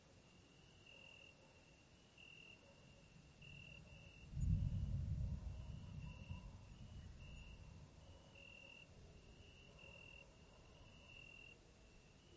自然的声音 " 雷霆室内2
描述：记录从室内，雷鸣般的雷鸣。雷电雷雨天气雷雨滚滚雷声隆隆声
标签： 闪电 天气 滚动雷 雷暴 雷暴 隆隆
声道立体声